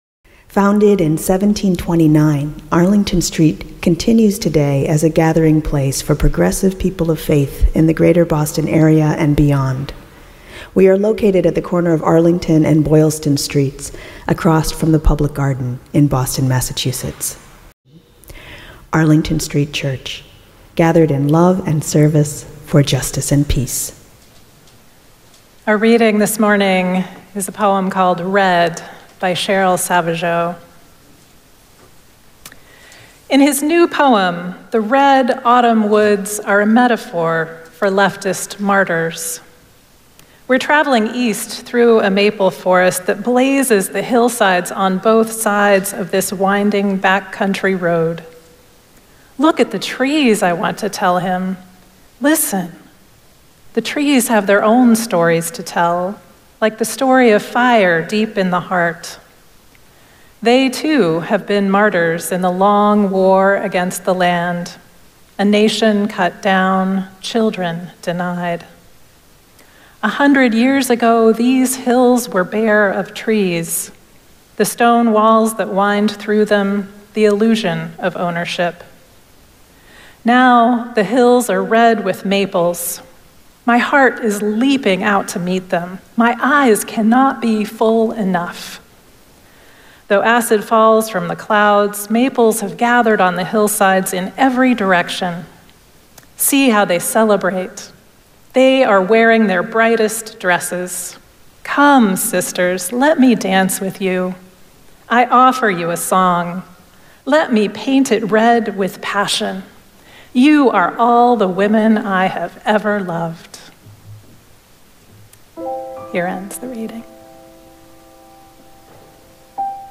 The sermon podcast from Arlington Street Church delivers our weekly sermon to listeners around the world.